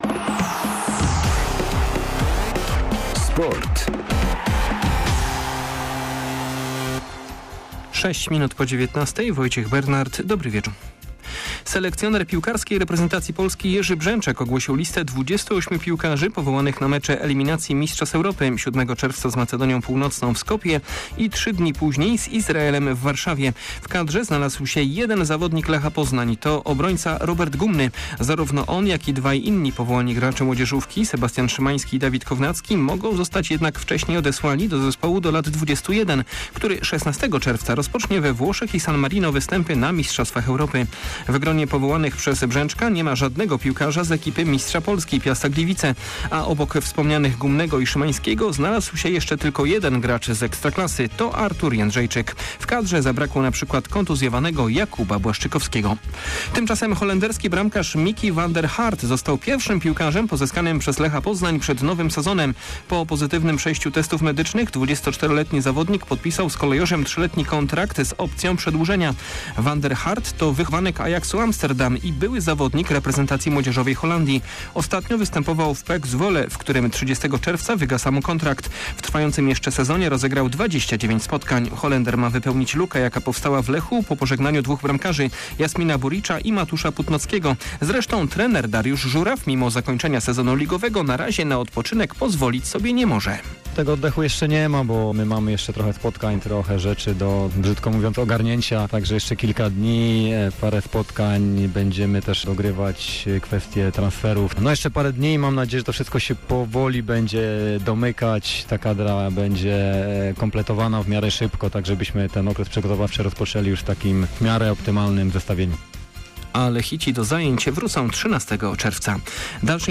21.05. SERWIS SPORTOWY GODZ. 19:05